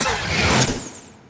attk 2 magic bolt.wav